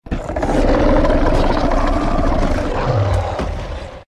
Wartdogroar2.ogg